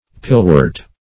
pillwort - definition of pillwort - synonyms, pronunciation, spelling from Free Dictionary
Pillwort \Pill"wort`\, n. (Bot.)